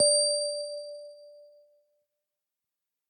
combobreak.ogg